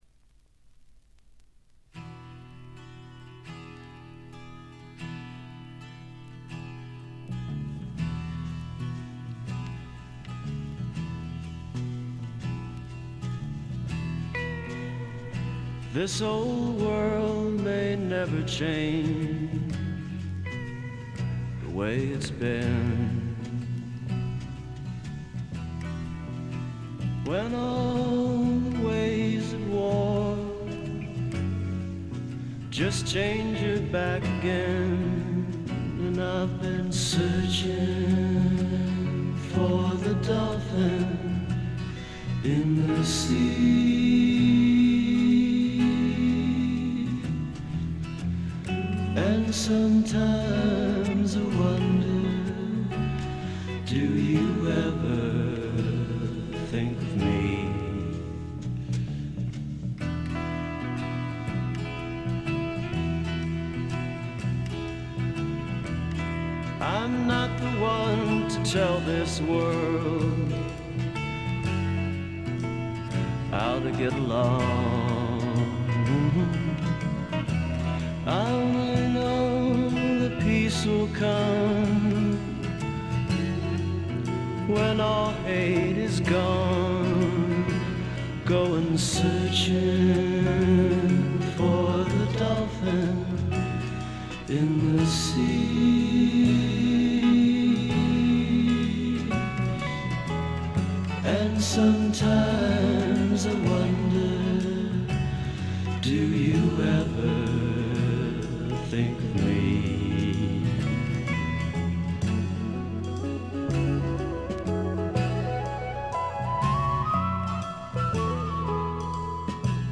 軽微なチリプチ程度。
試聴曲は現品からの取り込み音源です。